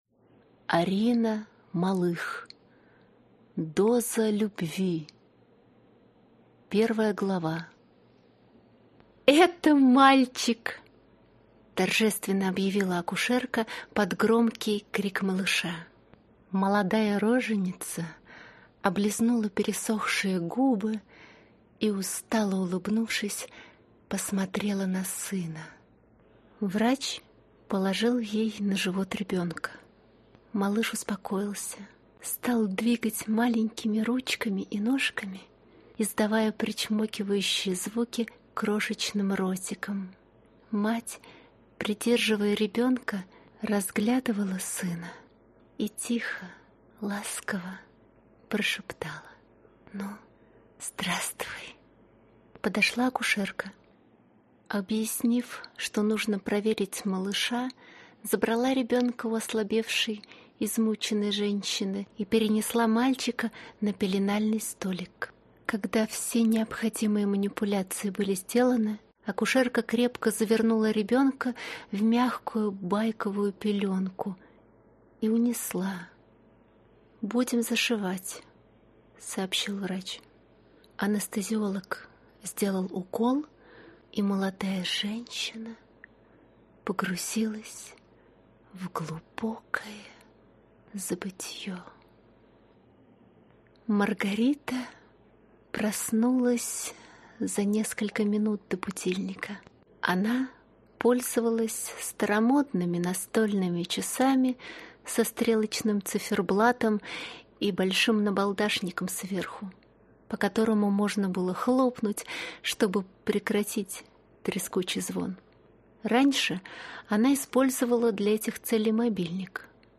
Аудиокнига Доза любви | Библиотека аудиокниг